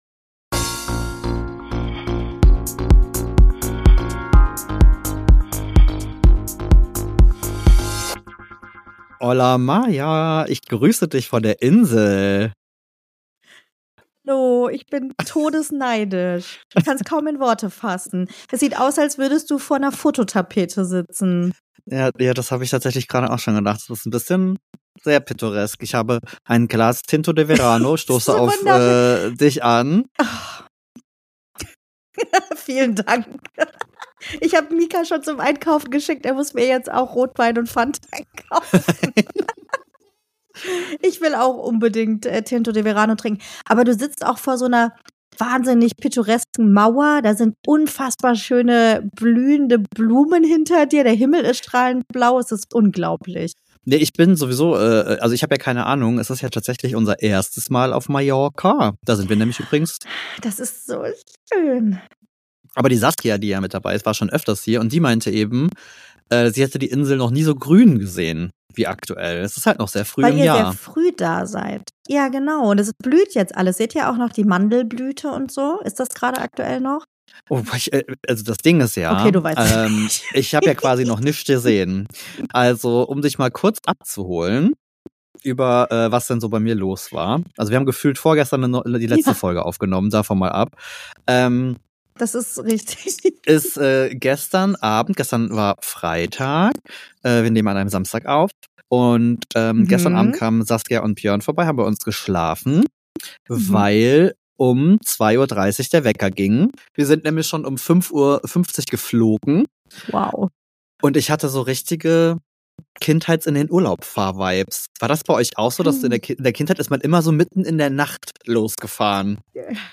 Und auch der zeitliche Versatz ist nicht wirklich förderlich für ein fließendes Gespräch...